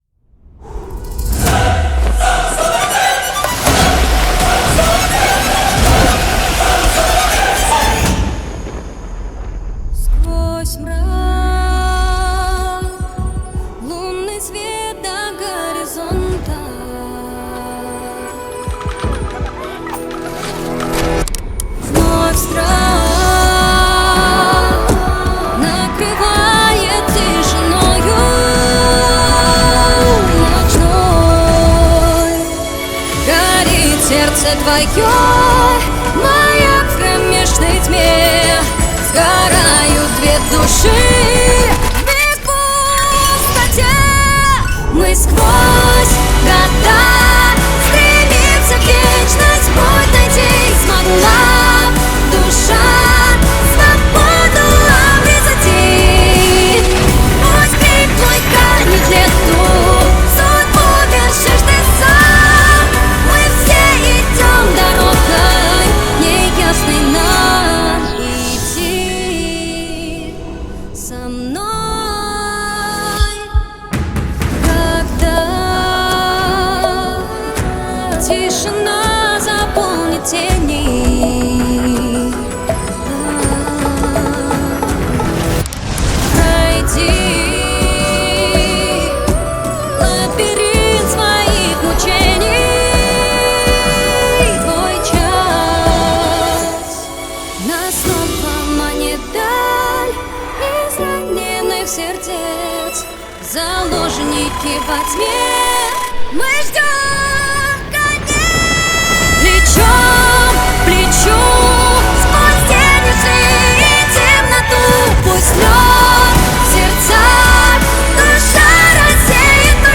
(кавер на русском)/(Russian cover)